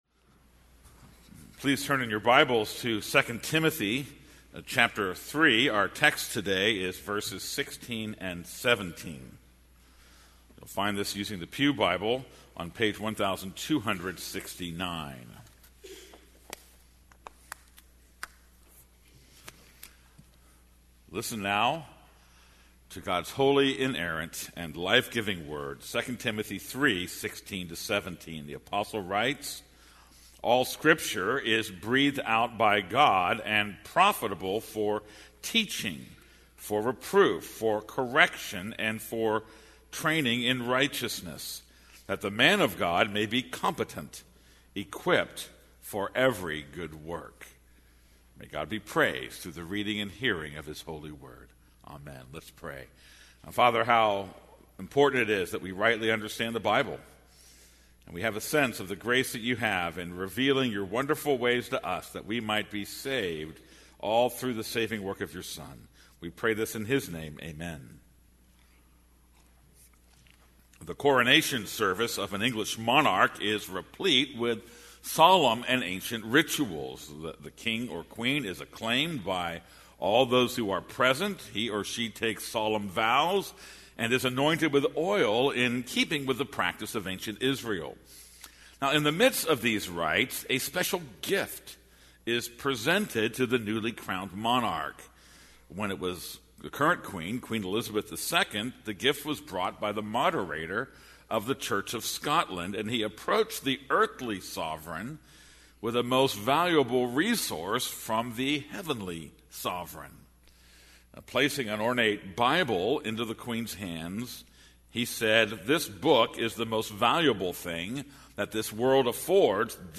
This is a sermon on 2 Timothy 3:16-17.